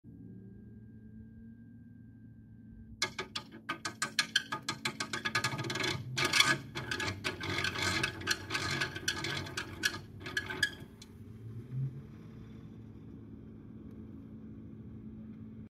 Bruit démarrage ventilateur Hitachi.mp3
J'ai remarqué que le ventilateur fait un bruit anormal au démarrage.
Ensuite, lorsqu'il atteint sa vitesse nominale, il n'y a pas de bruit particulier.
bruit-de-marrage-ventilateur-hitachi.mp3